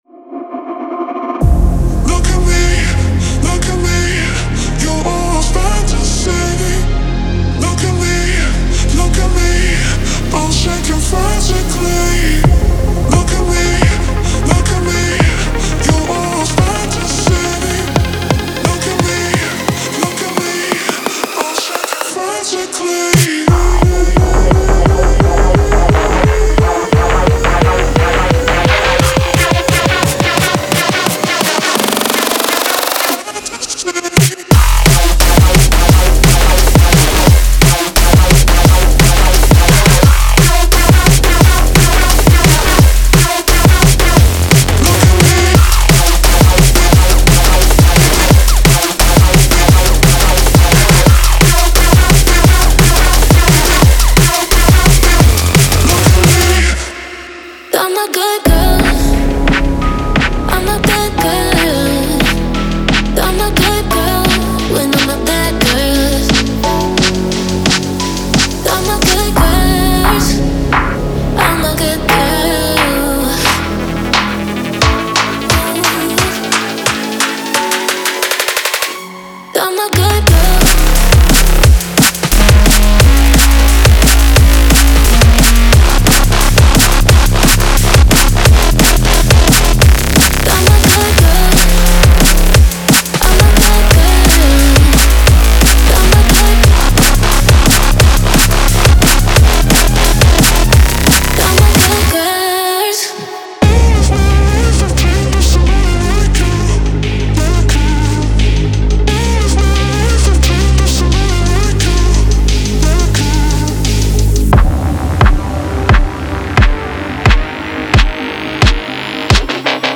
Genre:Drum and Bass
女性ボーカルのフックは中毒性があり、サウンドデザインは最先端。
ご注意：オーディオデモは、音量が大きく、圧縮され、統一感が出るよう処理されています。
174 – 176 BPM
47 vocal loops (hooks, chops)